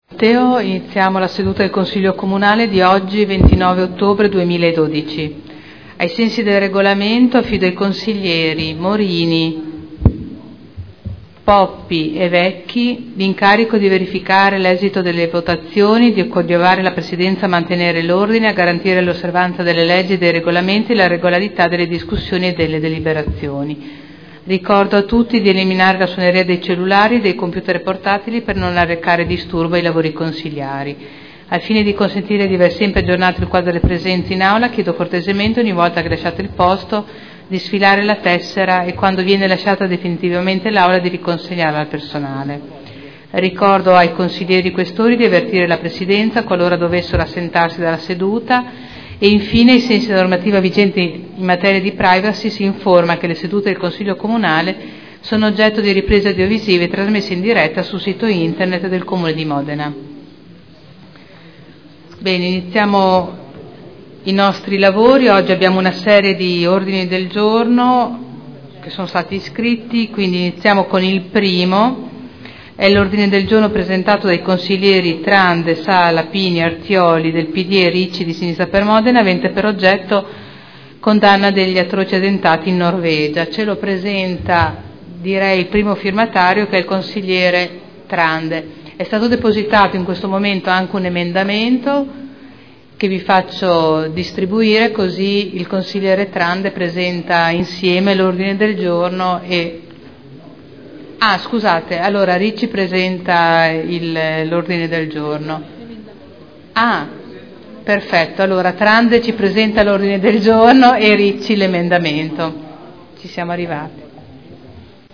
Seduta del 29/10/2012. Il Presidente Caterina Liotti apre i lavori del Consiglio Comunale